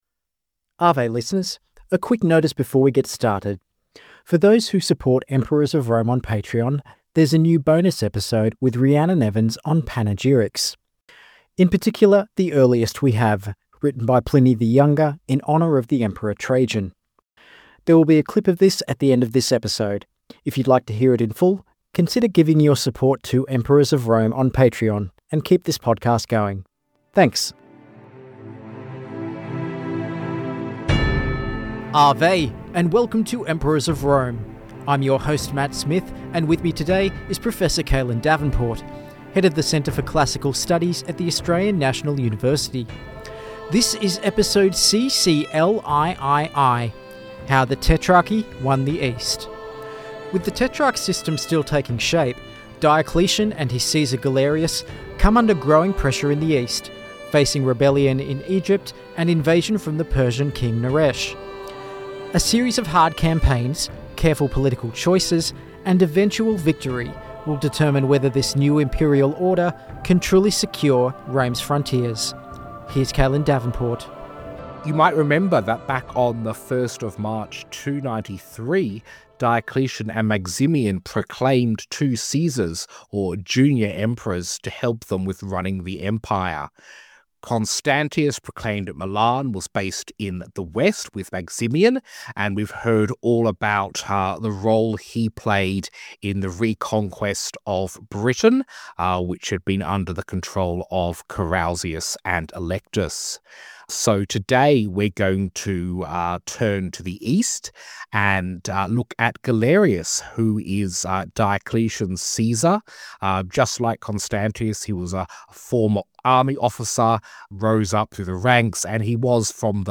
The Roman fort functioned as a base of operations for the army, a defensive and functional structure that could protect both the frontier of the Roman Empire and the supply lines. Guest: Dr Adrian Goldsworthy (historian and author, whose most recent work of fiction is titled 'The Fort').